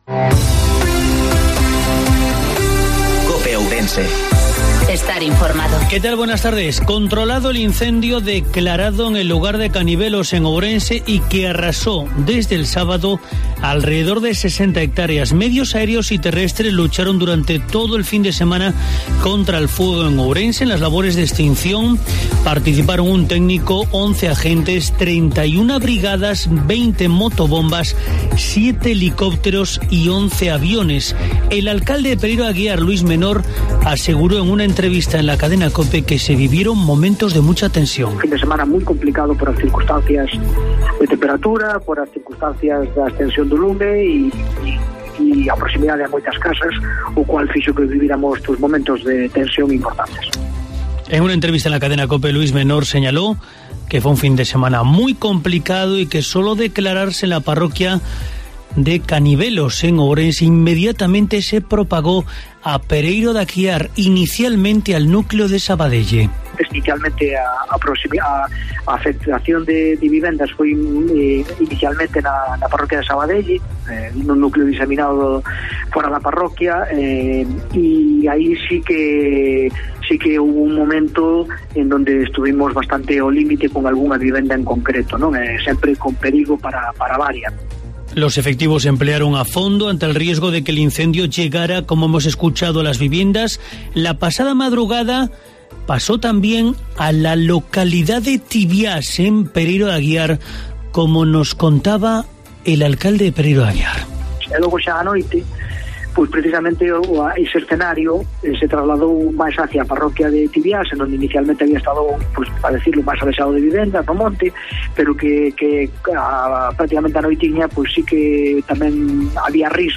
INFORMATIVO MEDIODIA COPE OURENSE